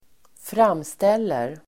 Uttal: [²fr'am:stel:er]